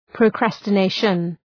Προφορά
{prə,kræstə’neıʃən}